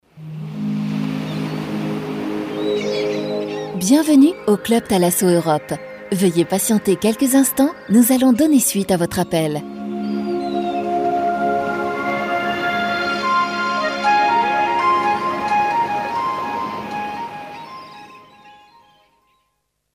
Grace à notre banque de données sonores assez fournie, vous pouvez enrichir votre attente téléphonique à l'aide de bruitages divers et variés.
Sur simple demande, Le Studio peut également procéder à l’enregistrement d’un échantillon sonore unique et original, adapté à votre entreprise.